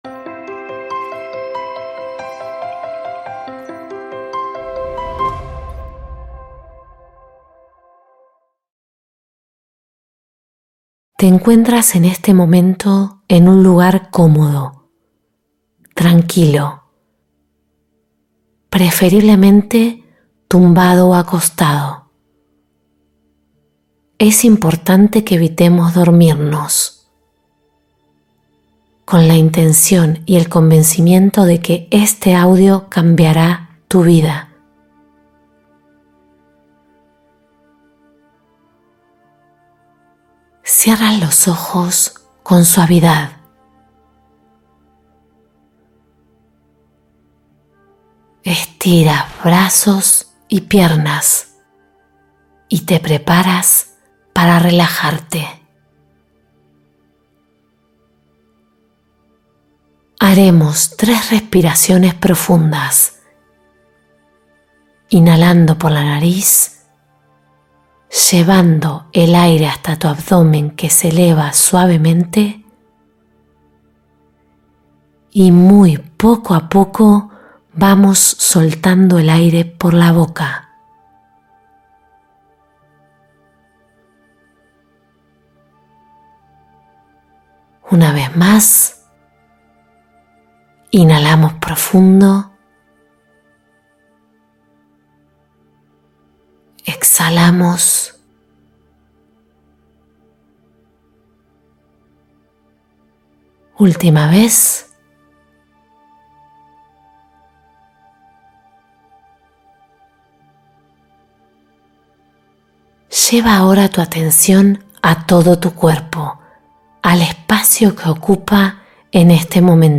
Libera bloqueos emocionales y mentales con esta meditación guiada profunda